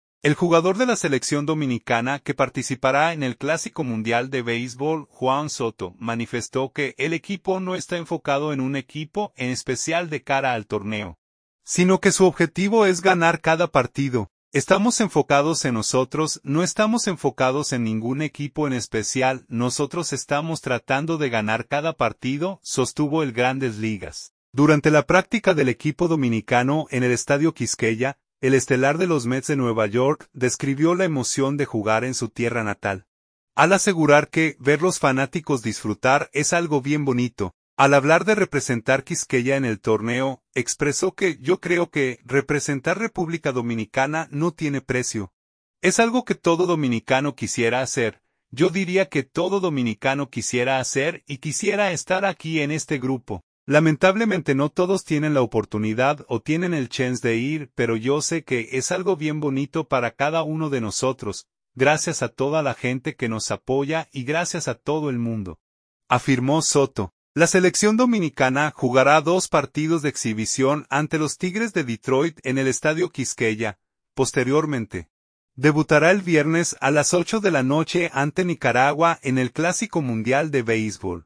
Durante la práctica del equipo dominicano en el Estadio Quisqueya, el estelar de los Mets de Nueva York describió la emoción de jugar en su tierra natal, al asegurar que ver los fanáticos disfrutar es “algo bien bonito”.